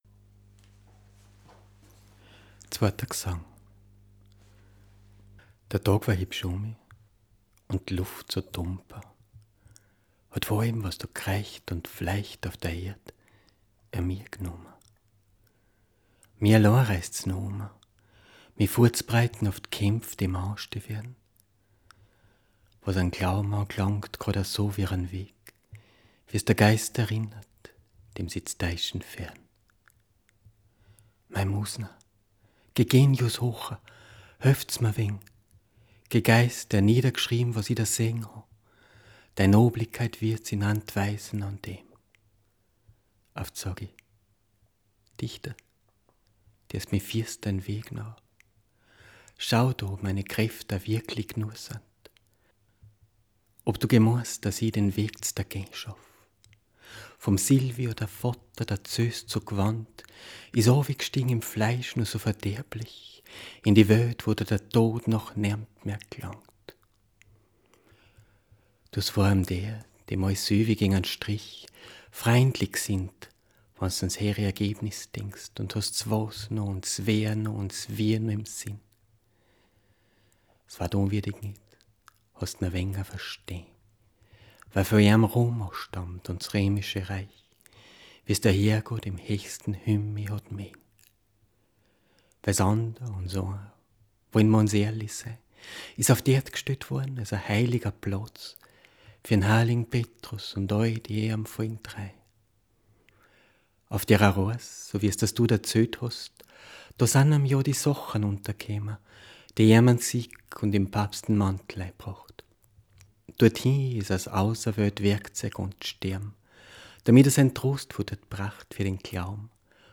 - ♫ Erste Audio-Skizze - (one-take recording - verleser ausgschnittn) ♫